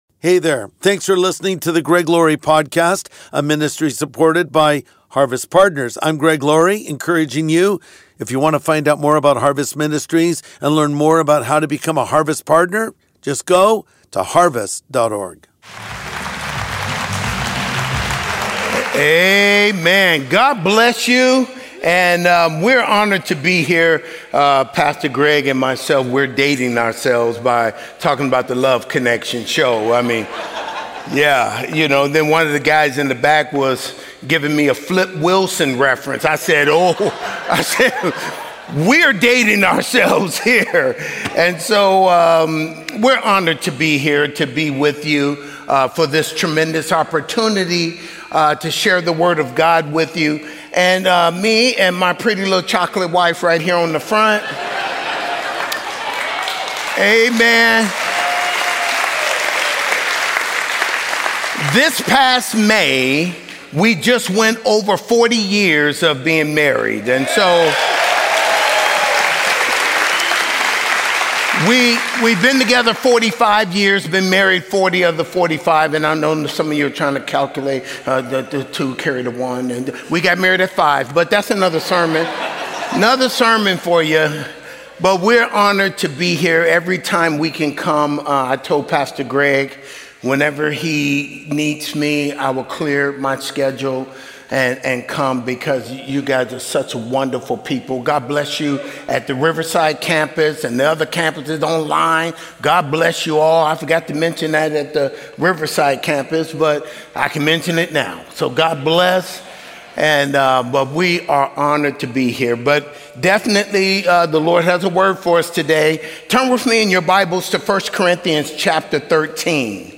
Love Connection | Sunday Message